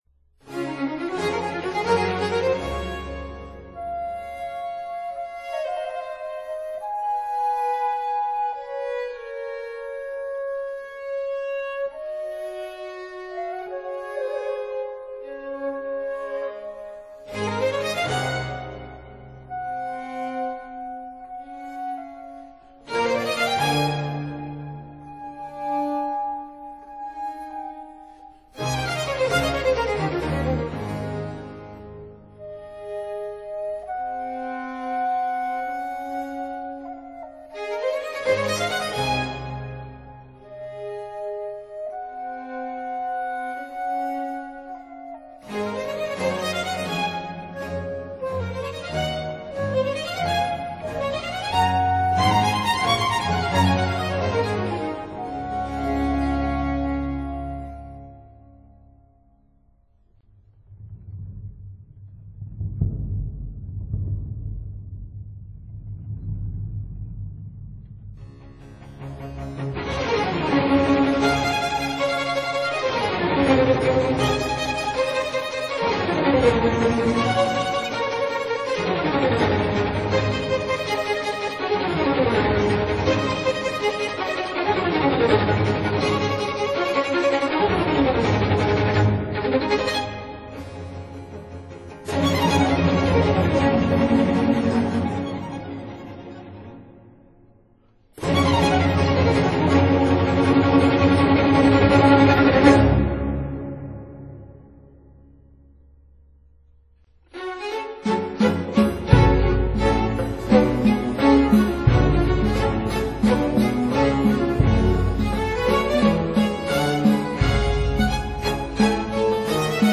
opéra-ballet